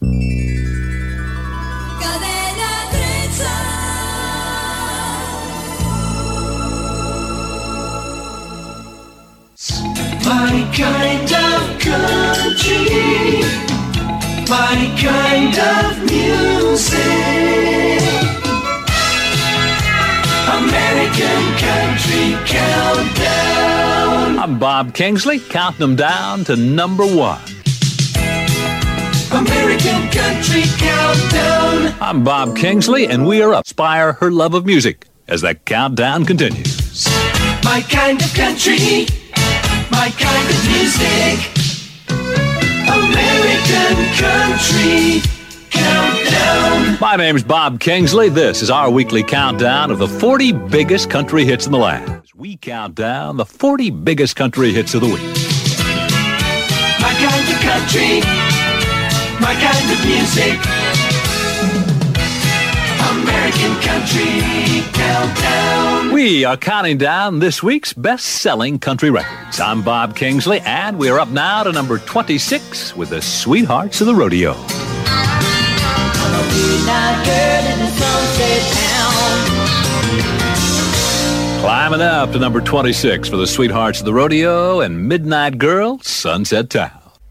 Indicatiu de l'emissora, indicatiu del programa, presentació d'un tema musical.
Musical